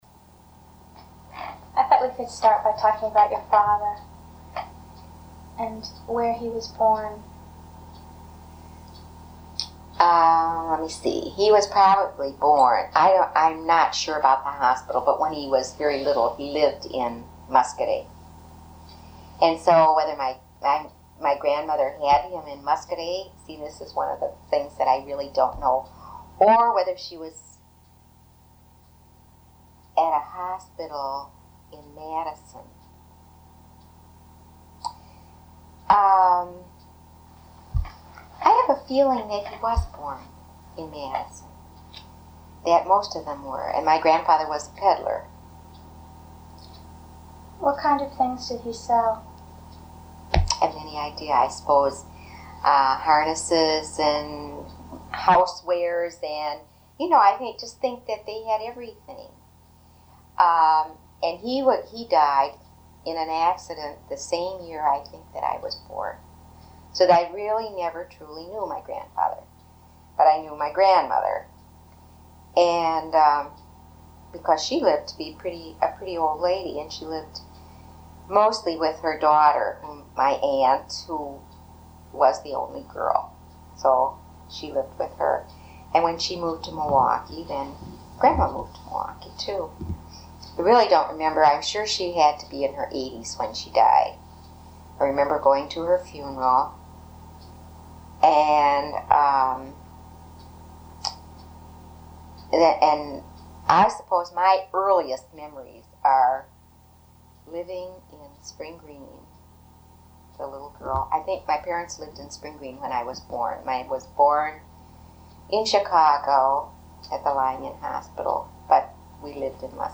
Wisconsin Historical Society Oral History Collections